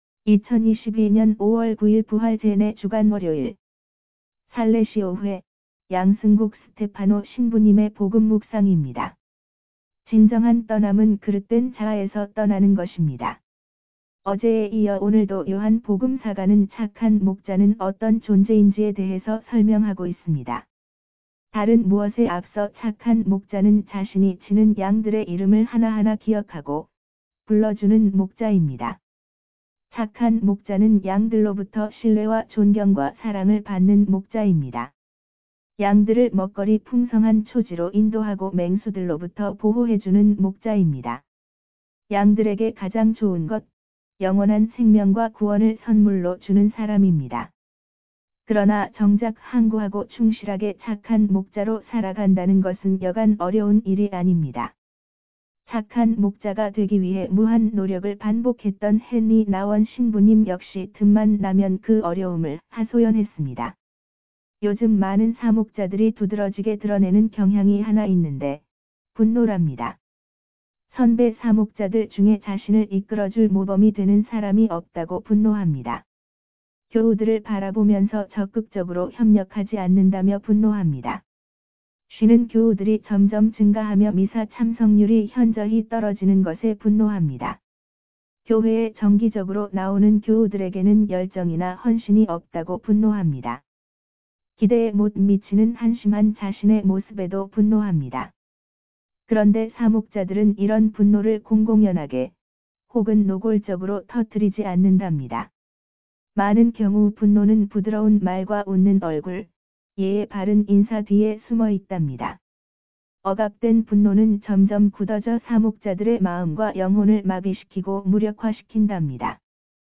강론